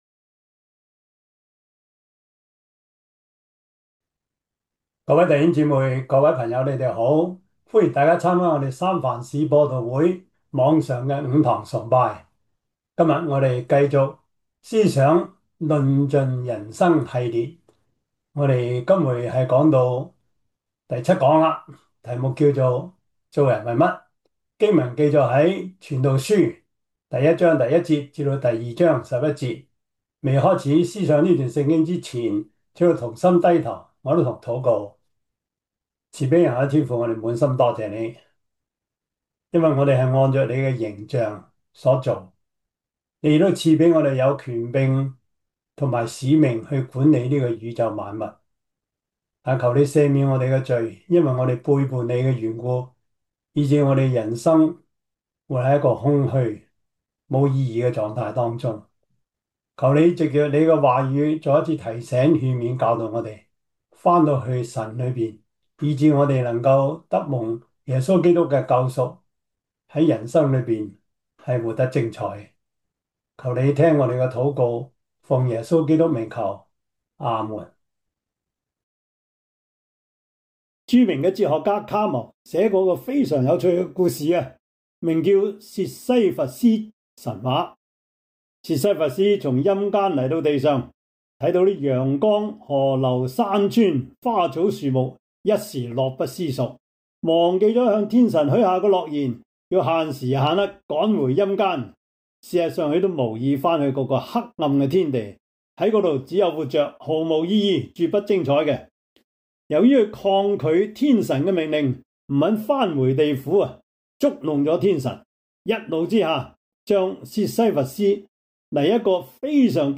傳道書 1:1-2:11 Service Type: 主日崇拜 傳道書 1:1-18 Chinese Union Version
Topics: 主日證道 « 齊來崇拜-活出受,齊成長 復興, 成長, 豐盛 »